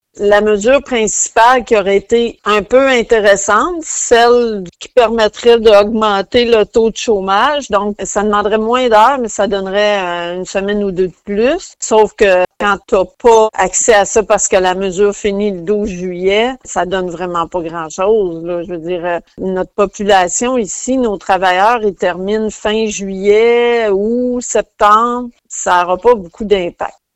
En entrevue sur les ondes de Radio-Gaspésie, elle a indiqué que les travailleurs saisonniers en retireront peu de bénéfices.